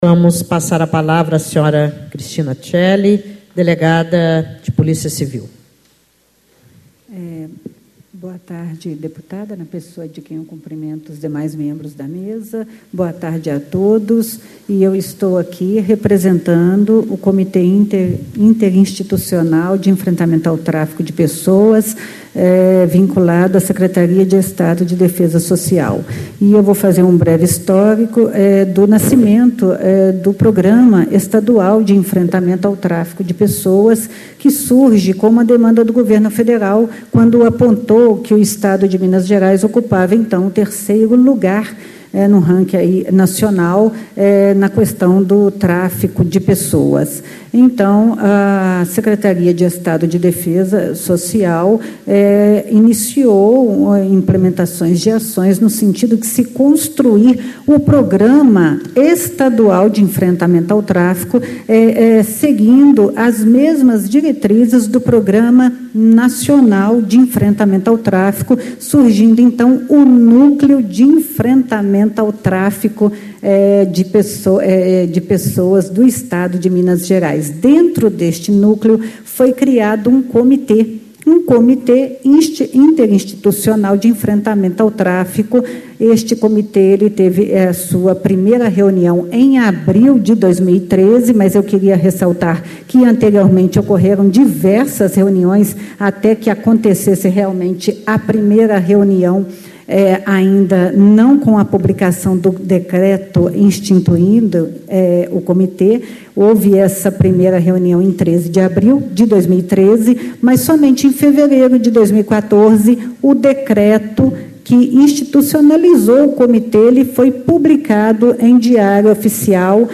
Ciclo de Debates Enfrentamento do Tráfico de Pessoas em Minas Gerais
Discursos e Palestras